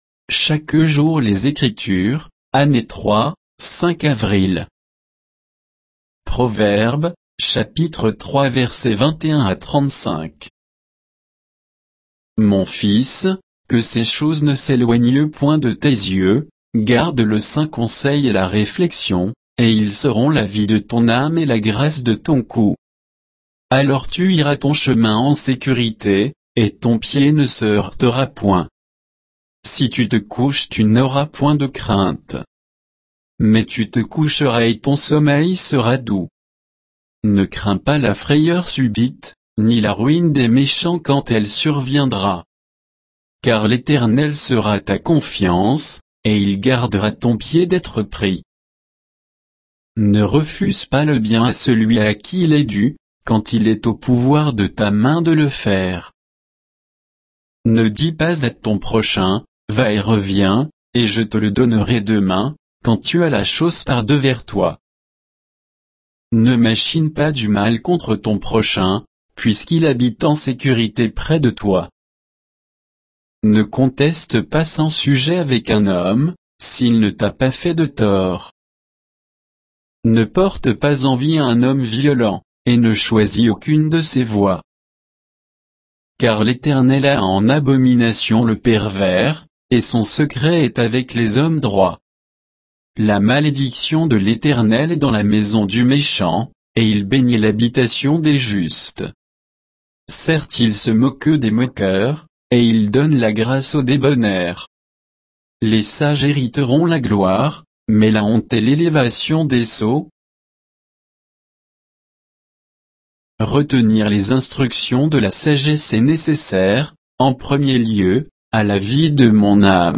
Méditation quoditienne de Chaque jour les Écritures sur Proverbes 3, 21 à 35